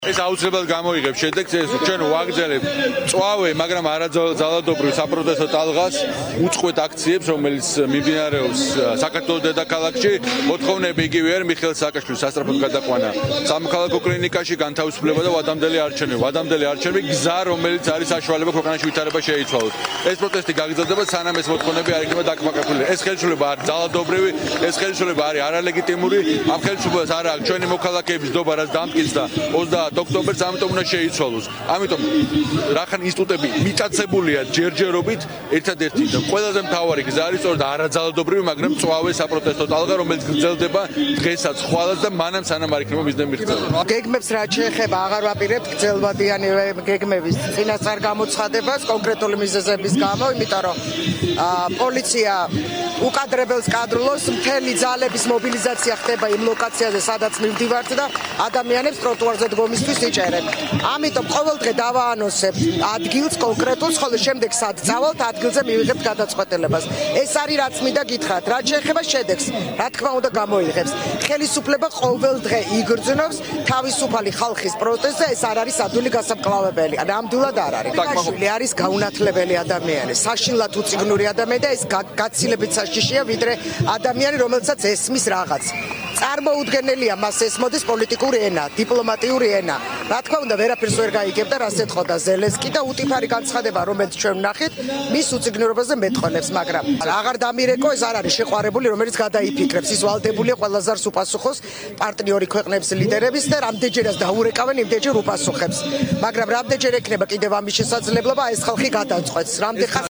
აქციის მონაწილეების ხმა